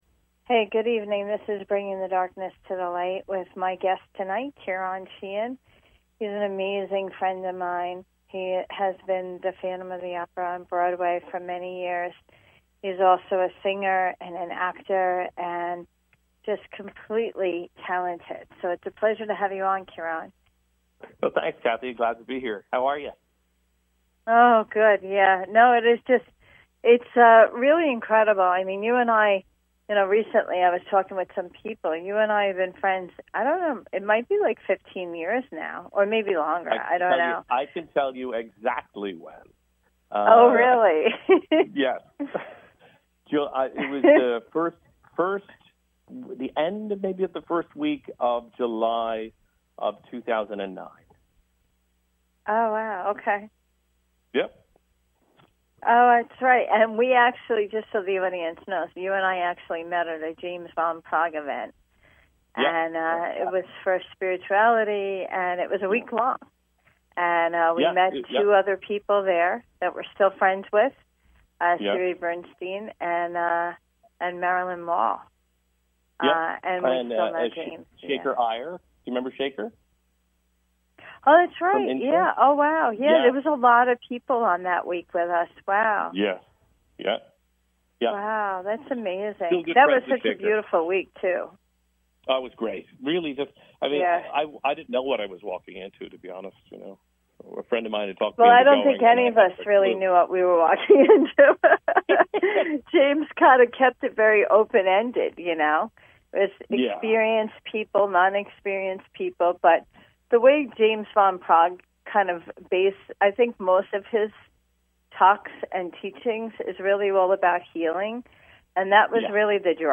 Talk Show Episode, Audio Podcast
A CALL IN SHOW!